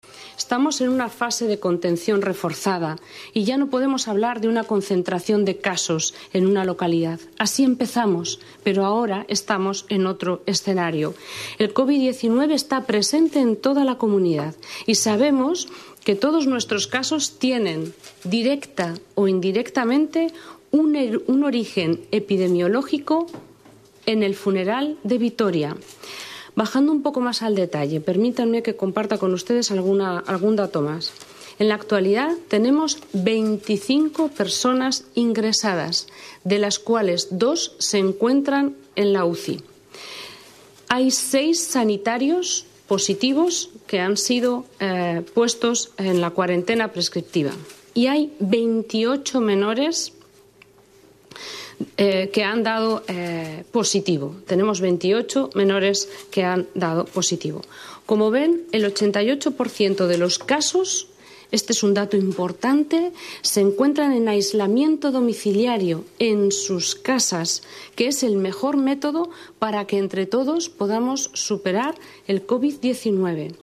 La consejera de Salud, Sara Alba, informa de que todos los positivos que se han registrado en la región han tenido su origen, directa o indirectamente, en el funeral de Vitoria.